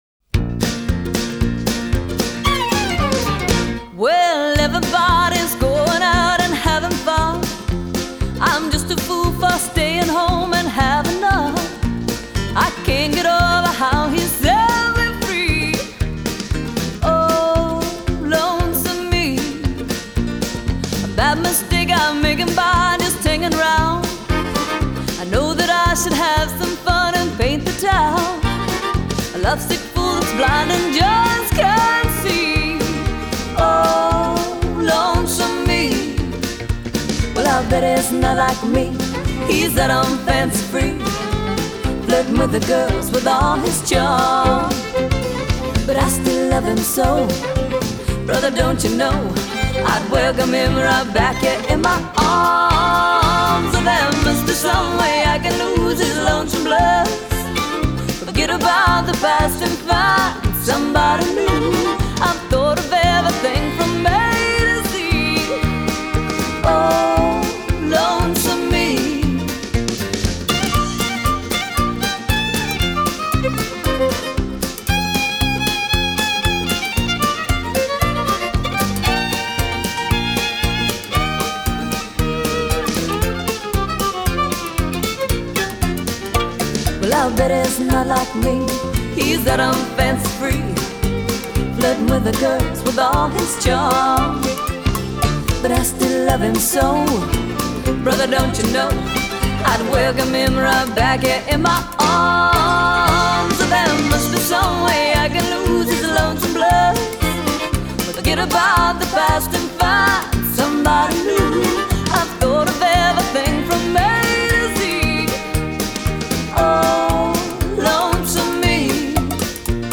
另外配器效果也是极品，吉它、钢琴、SAX音声通透见底。
此曲曲风尤其奔放，酷似西班牙舞曲，听者如置身于西班牙年庆人群之中舞之蹈之，而不能自拔。